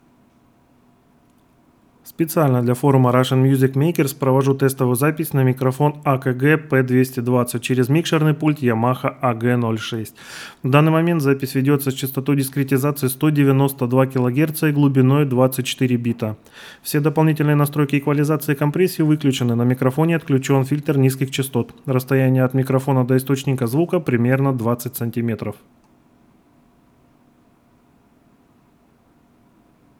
Вот пример записи звука с микрофона в моих условиях. Это запись в максимально тихое время, когда соседи на работе и не шумят. Только системник под столом слева примерно в метре от микрофона. Кондиционеры не работают, ничего больше не работает.